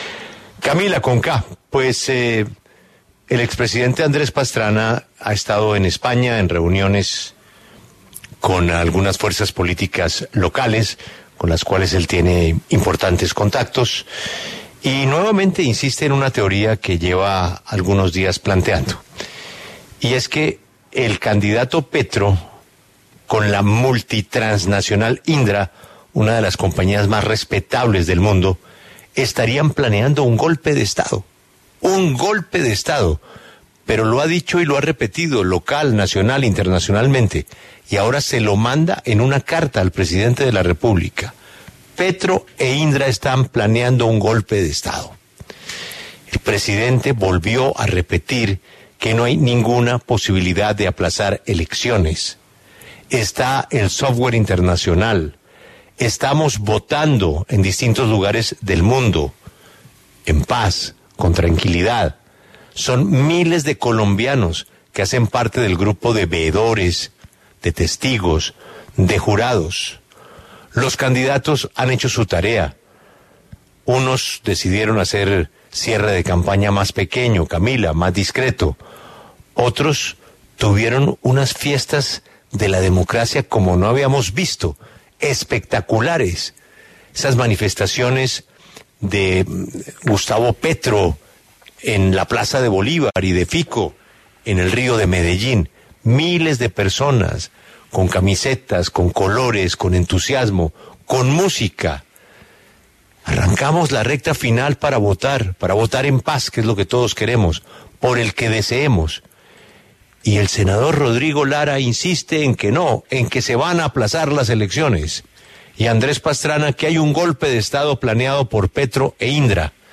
En La W, Luis Fernando Velasco, Iván Marulanda y Juan David Vélez hacen un balance sobre el clima electoral de cara a la primera vuelta.